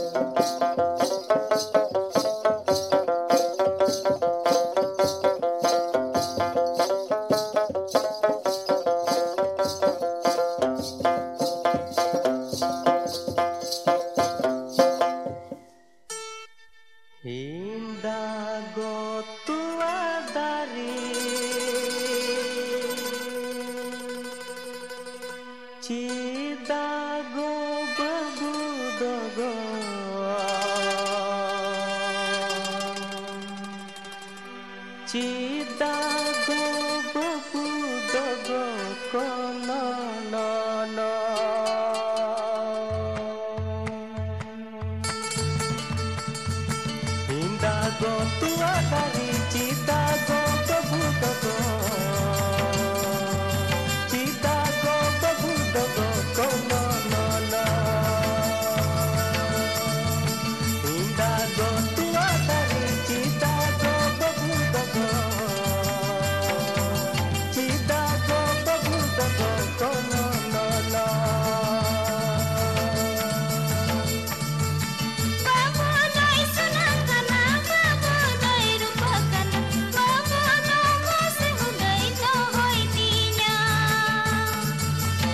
Santal tribal music